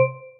point_score.wav